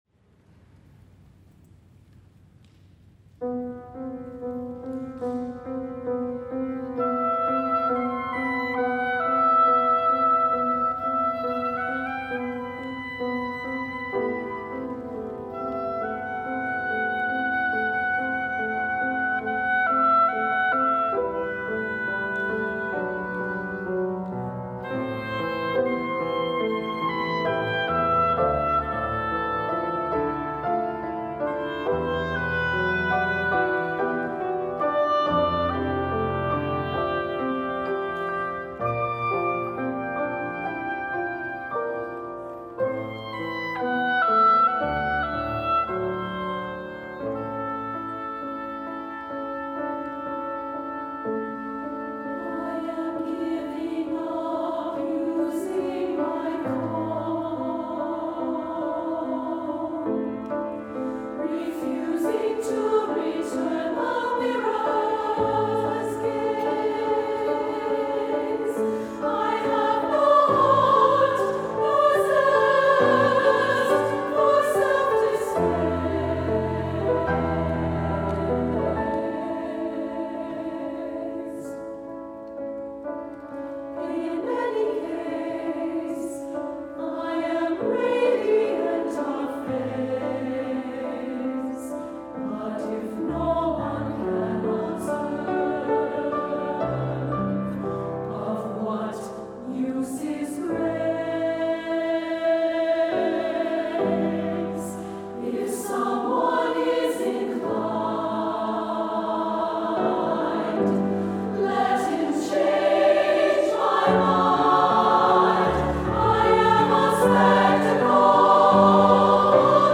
SSAA, piano and oboe